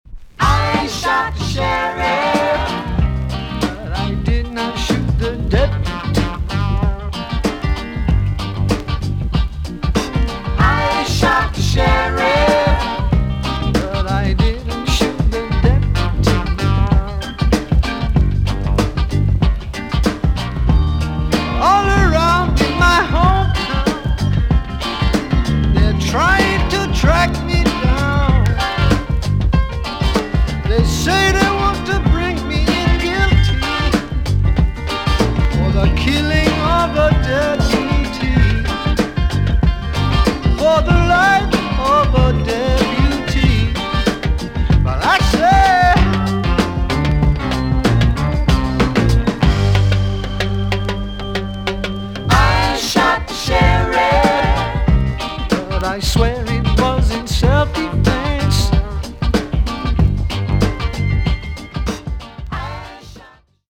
EX-音はキレイです。